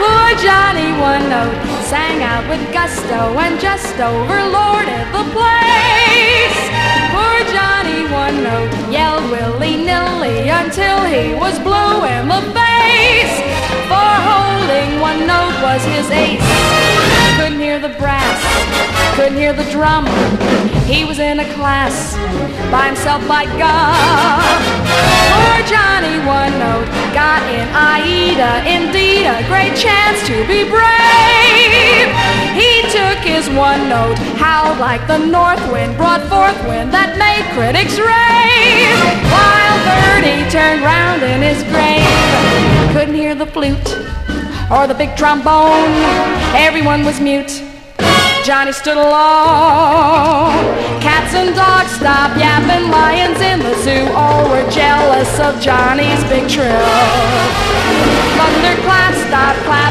ROCK / 70'S
テープ・コラージュやSEなど、サージェント・ペパーズ期のビートルズ的なアレンジが全編に亘って施さた名作！